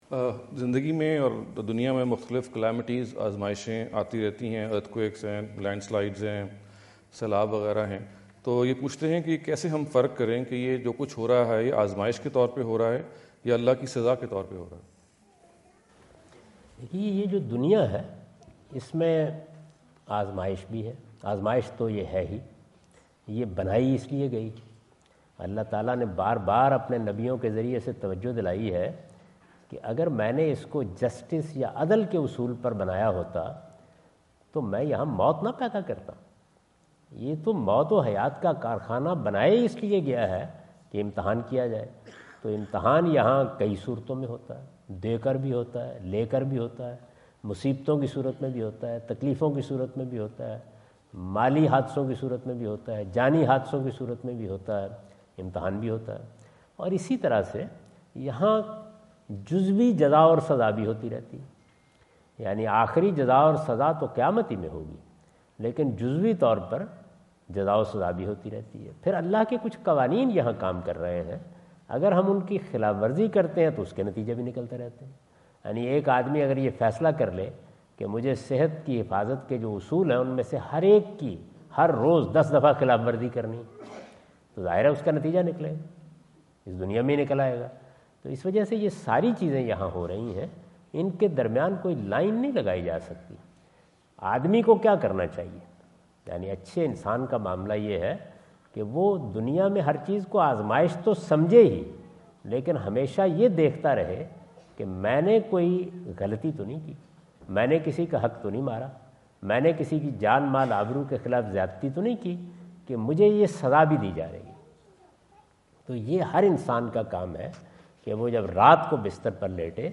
Javed Ahmad Ghamidi answer the question about "Calamities and Divine Punishment" asked at The University of Houston, Houston Texas on November 05,2017.
جاوید احمد غامدی اپنے دورہ امریکہ 2017 کے دوران ہیوسٹن ٹیکساس میں "دنیاوی مصیبتیں: آزمایش یا اللہ کا عذاب" سے متعلق ایک سوال کا جواب دے رہے ہیں۔